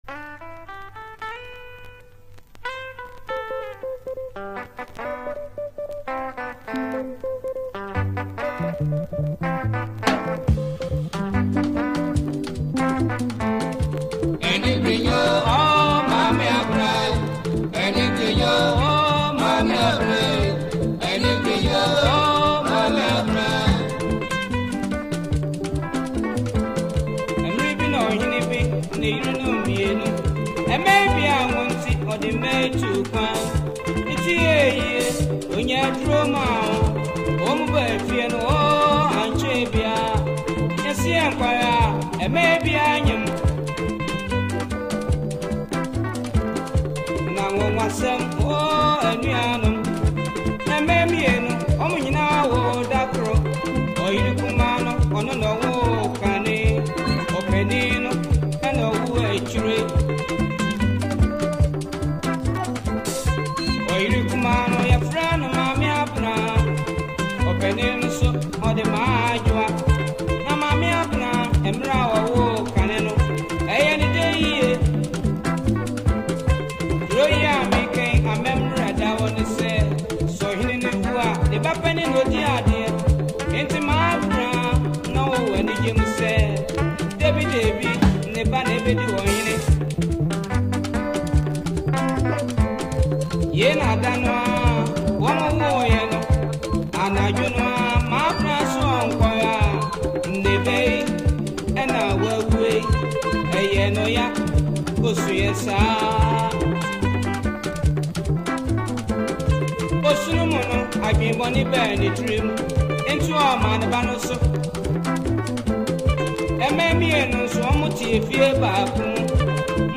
Ghana Highlife song